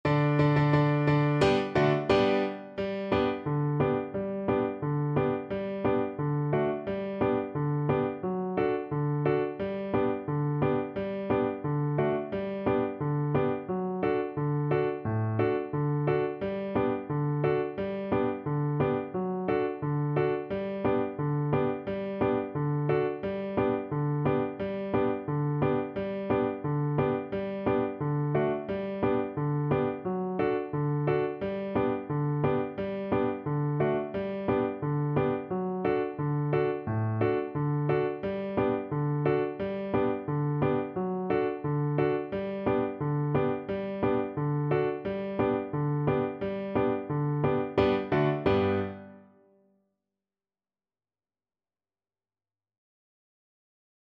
Steady march =c.88
2/4 (View more 2/4 Music)